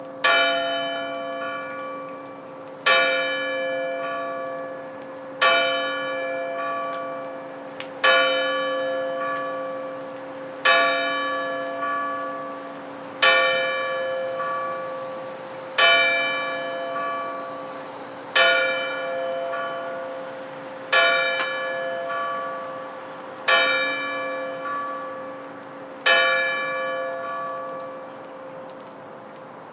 描述：大本钟的著名钟声被扭曲并分层。完美的荒诞主义。由我制作。
标签： 大笨钟 钟声 时钟 扭曲 混音
声道立体声